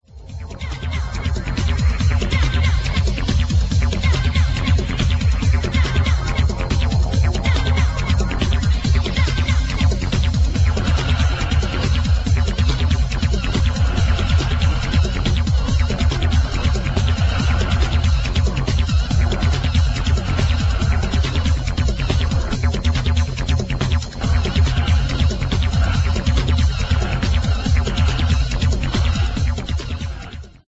Electro Techno Detroit